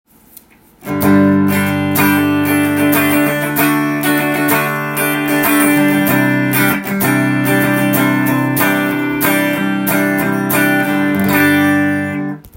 まずはコード弾きをしてみました。
ローポジションでもコードが弾きやすく　アルペジオもしやすい感じがしました。